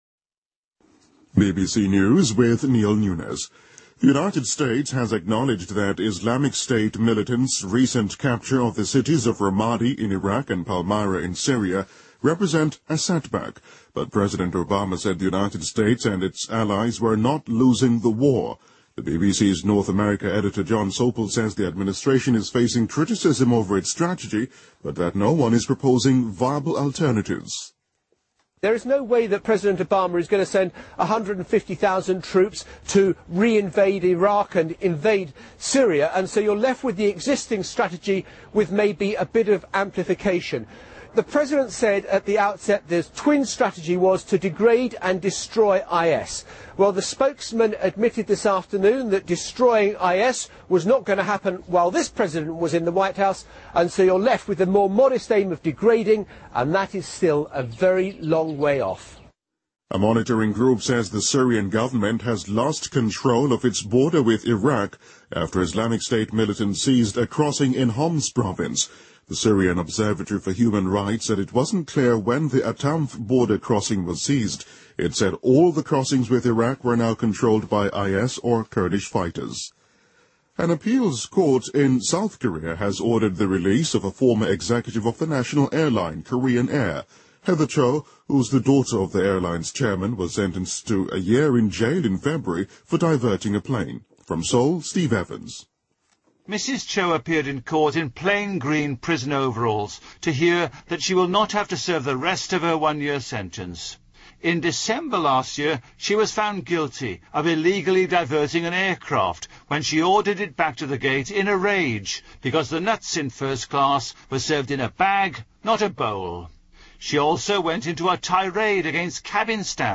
BBC news,大韩航空公司总裁赵显娥被判有罪